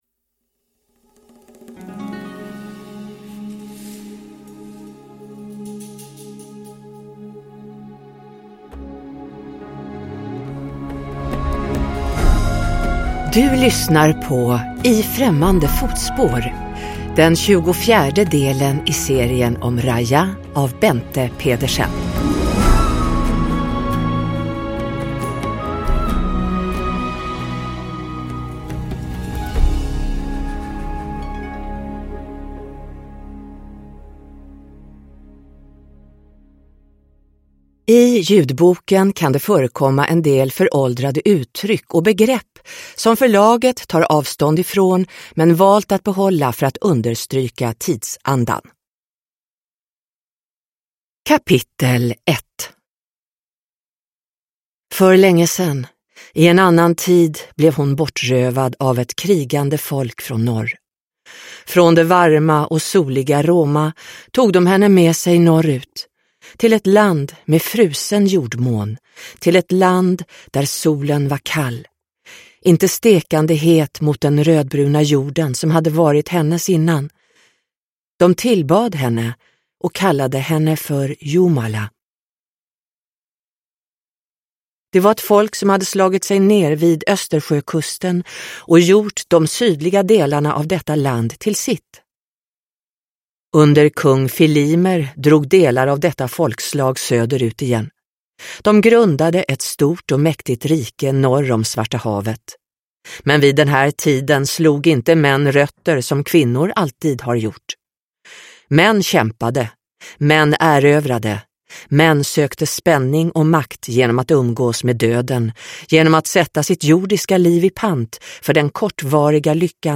I främmande fotspår – Ljudbok – Laddas ner